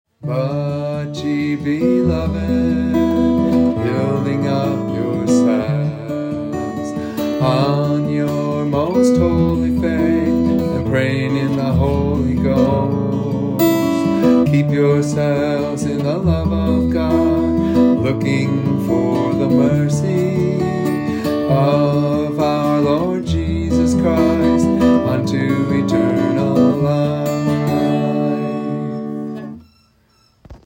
Transpose from D